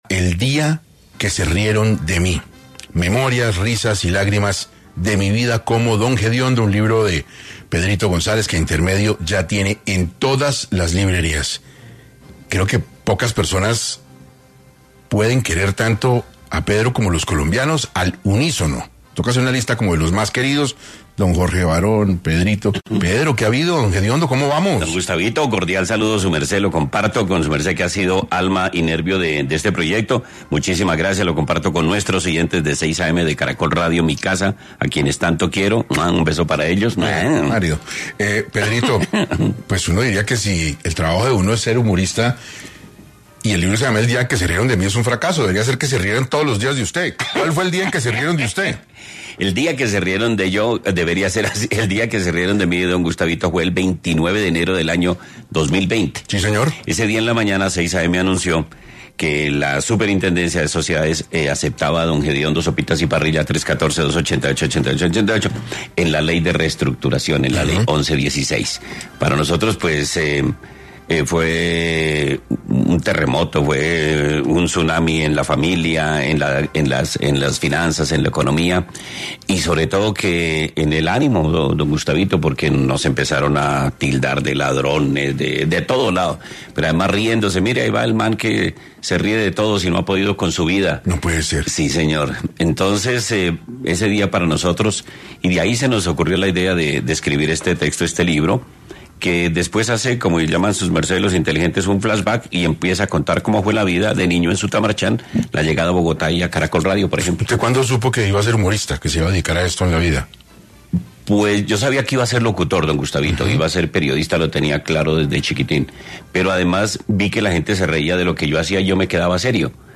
En entrevista con 6AM, “Don Jediondo” contó los detalles exclusivos sobre su historia en la radio que podrán encontrar en su nuevo libro.